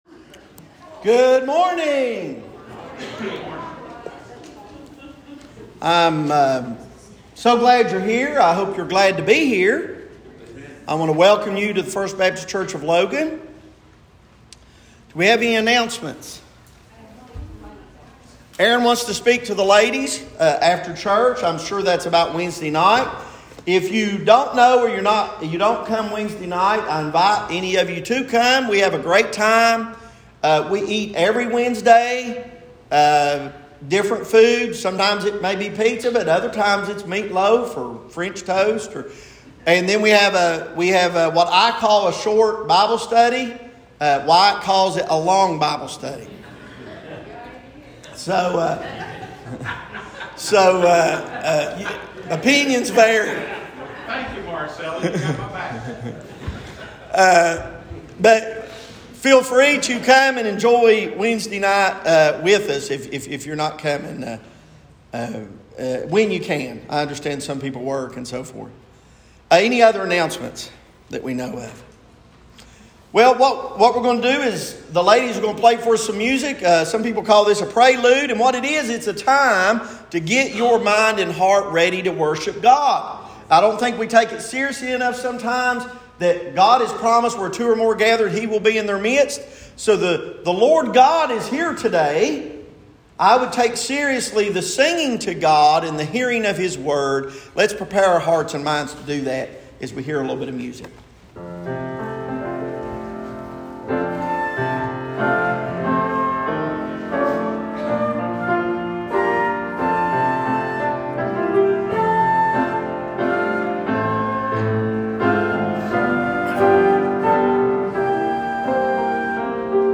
Sermons | First Baptist Church of Logan